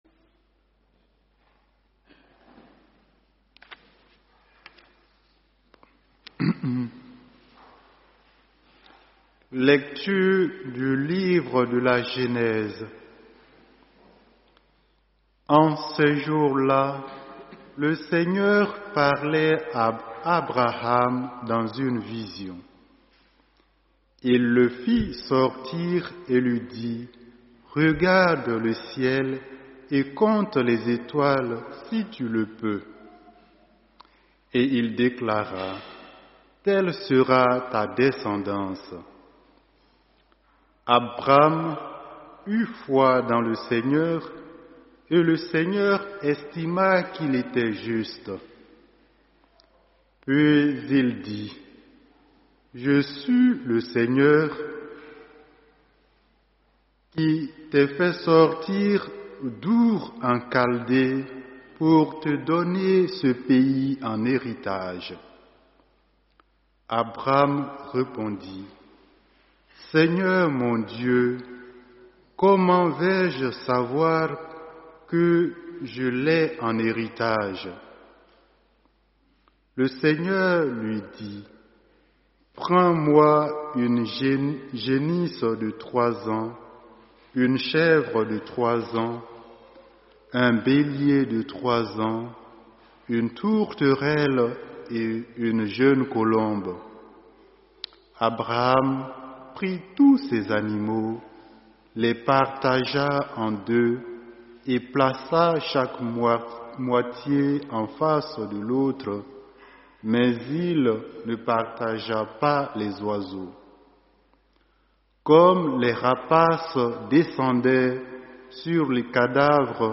Homélie : Frères et Sœurs, bonjour.